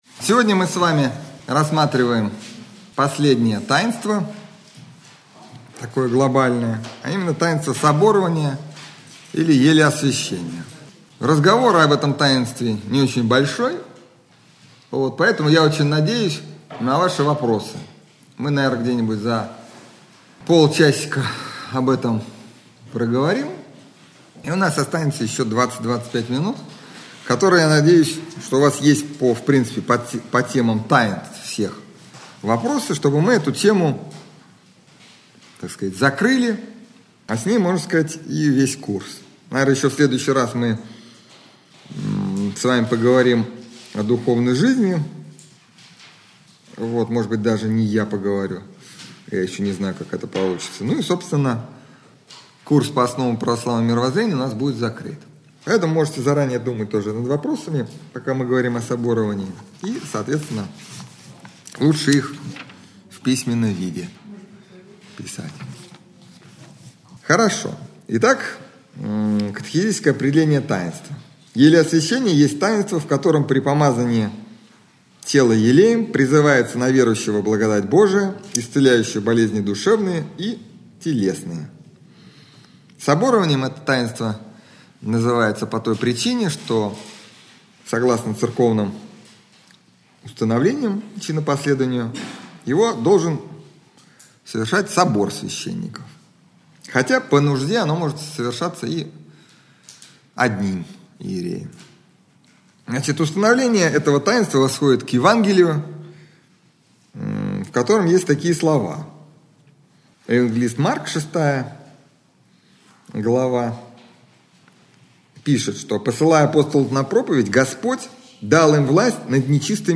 лекция